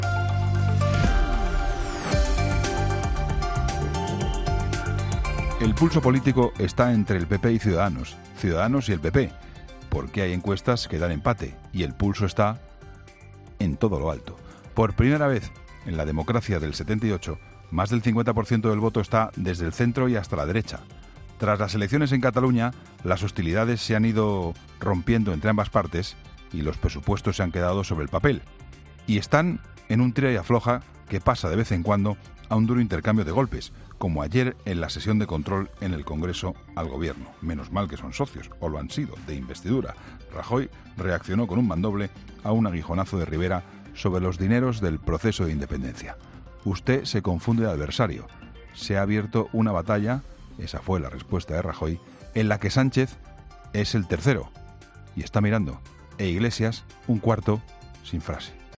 AUDIO: Escucha el comentario del director de 'La Linterna', Juan Pablo Colmenarejo, en 'Herrera en COPE'